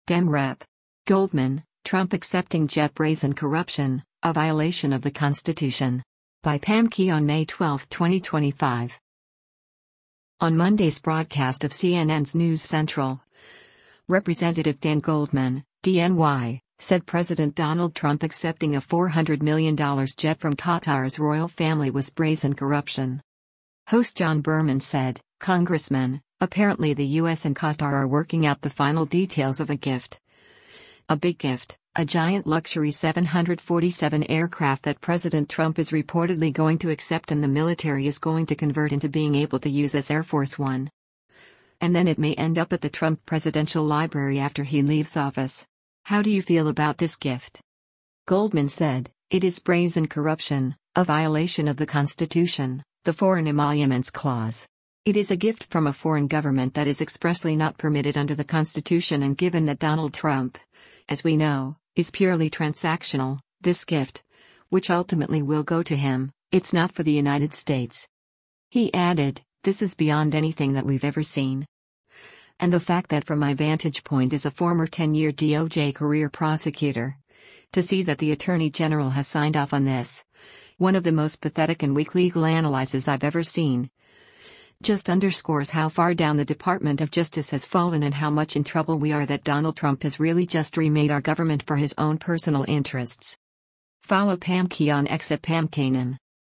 On Monday’s broadcast of CNN’s “News Central,” Rep. Dan Goldman (D-NY) said President Donald Trump accepting a $400 million jet from Qatar’s royal family was “brazen corruption.”